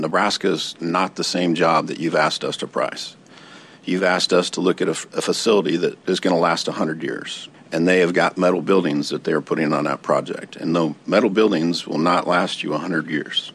The answer from a representative of contractor J-E Dunn.